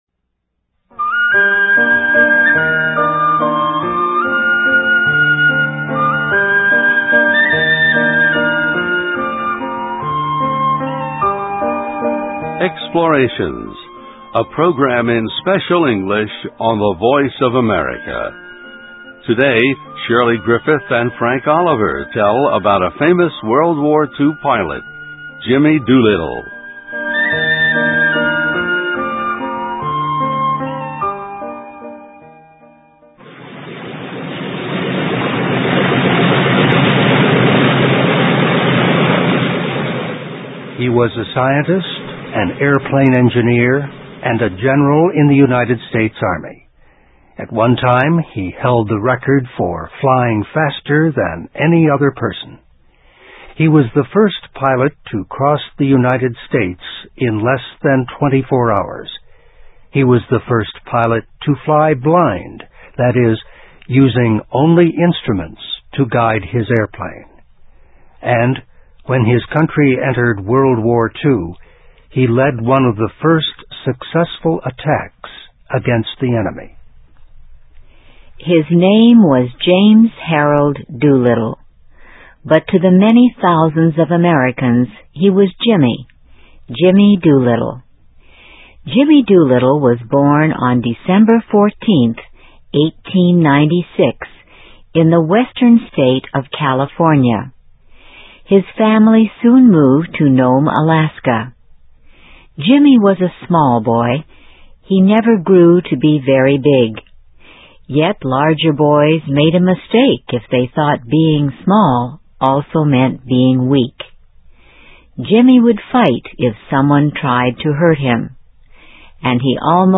EXPLORATIONS, a program in Special English on the Voice of America.